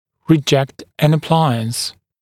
[rɪ’ʤekt ən ə’plaɪəns][ри’джэкт эн э’плайэнс]отвергать аппарат, отказываться от применения, ношения аппарата (о пациенте)